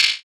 [Perc] Saint.wav